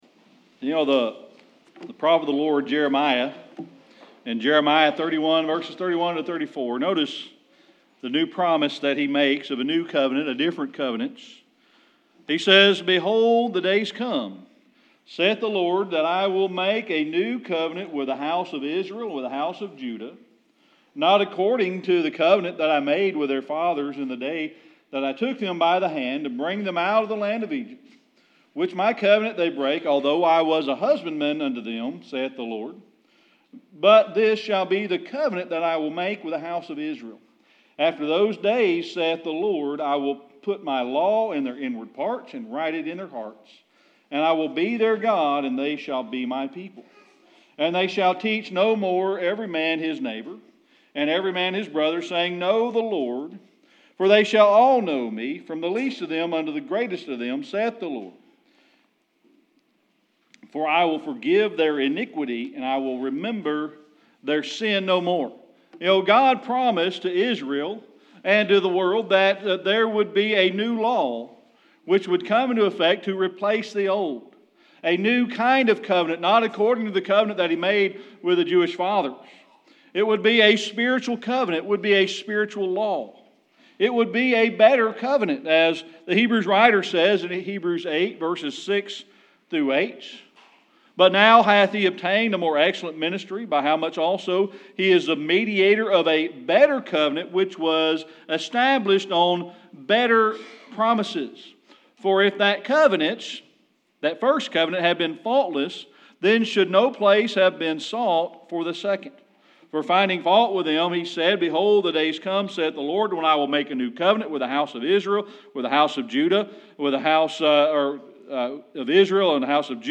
Passage: Acts 2:1-41 Service Type: Sunday Evening Worship The prophet of the Lord, Jeremiah, in Jeremiah 31:31-34 delivers an new kind of covenant.